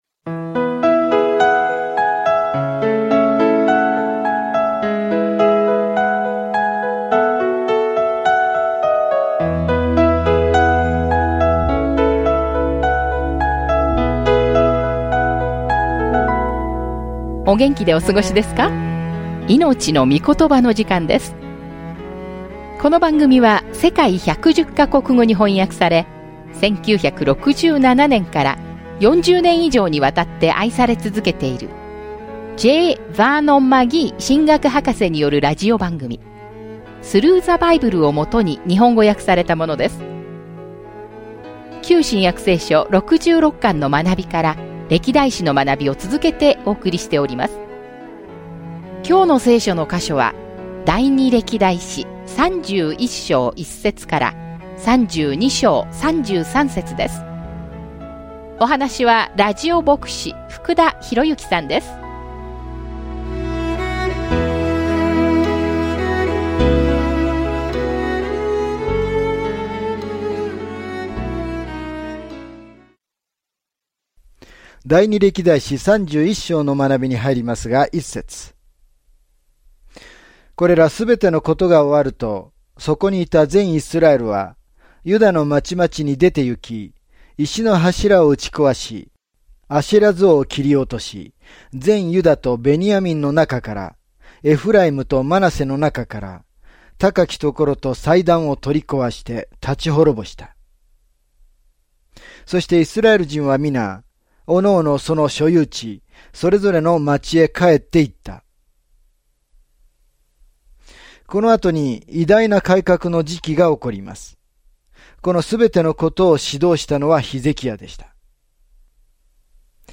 聖書 歴代誌下 31 歴代誌下 32 日 14 この読書プランを開始する 日 16 この読書プランについて 第二歴代誌では、イスラエルの歴代の王や預言者について聞いた物語を、別の視点から見ることができます。音声学習を聞き、神の言葉から選ばれた節を読みながら、2 つの歴代誌を毎日旅してください。